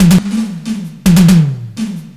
FILLTOMEL3-R.wav